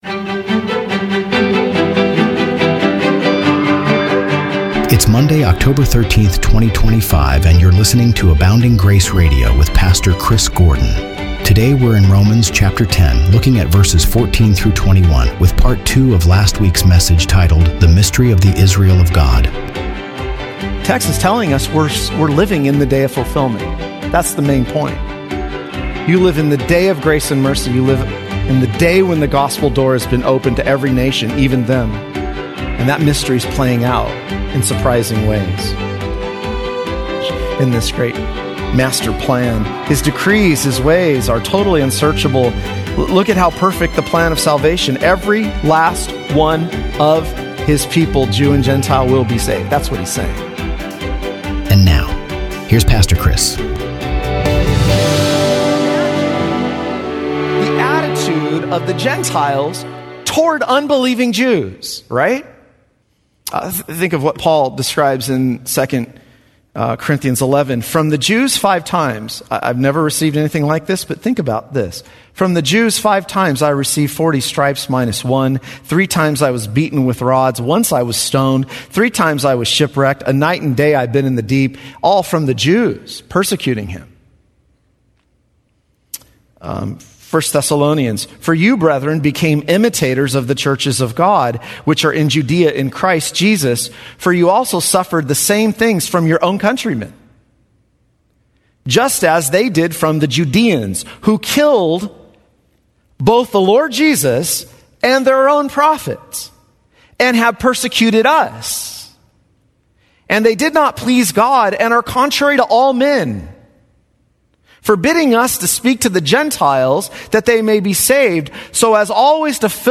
The sermon warned against Gentile pride and arrogance toward unbelieving Jews, reminding listeners that their salvation came through God's grace, not merit.